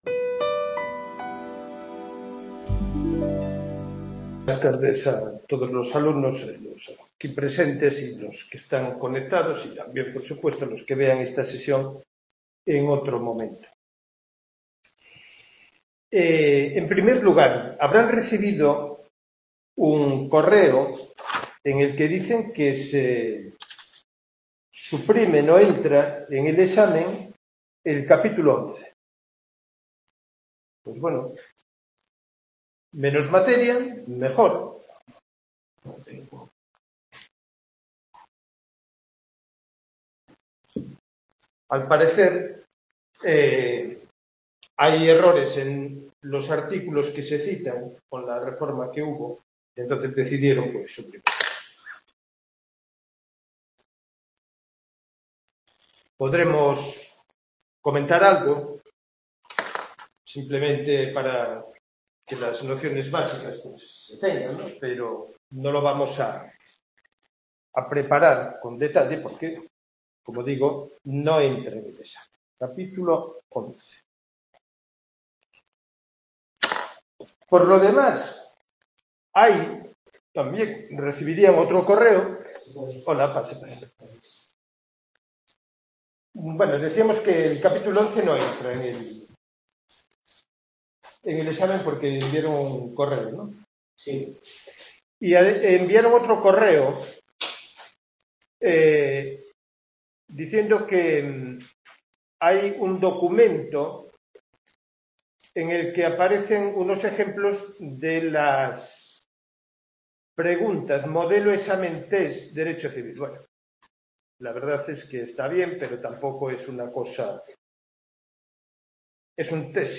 Tutoría